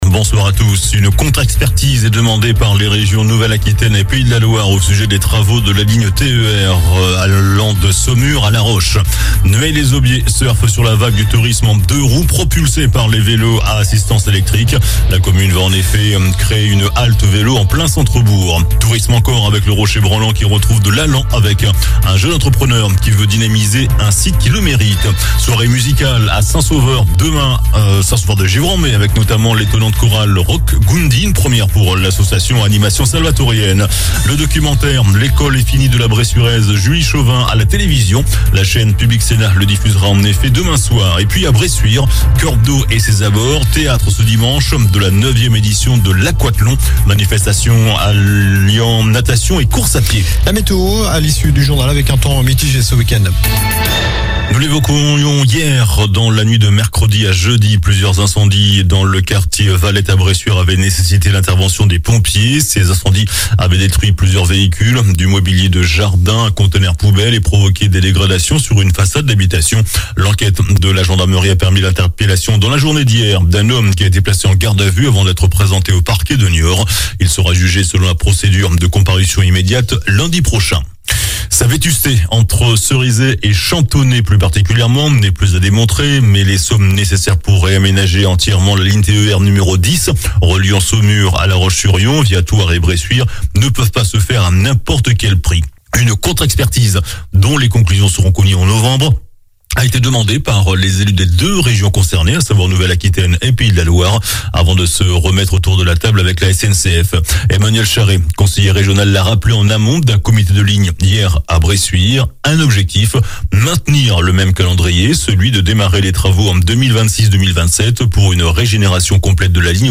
JOURNAL DU VENDREDI 09 JUIN ( SOIR )